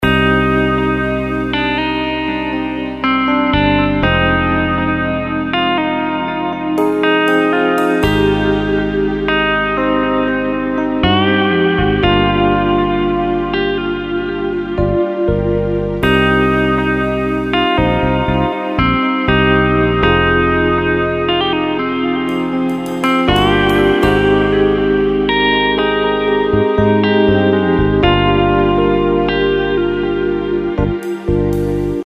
زنگ موبایل
رینگتون موبایل رمانتیک